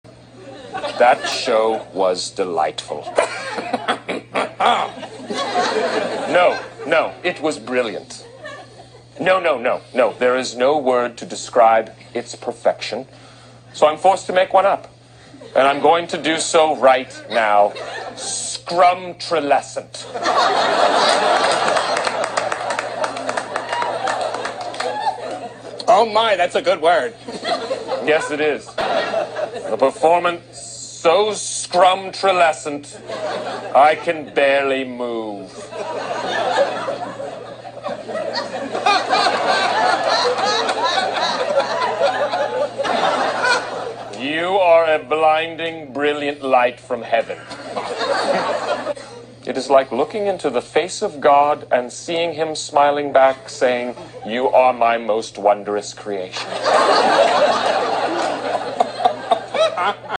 Will Ferrell and Alec Baldwin play on SNL the ``Inside the Actor's Studio" skit, a parody on James Lipton.